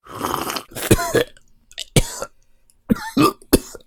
cough.ogg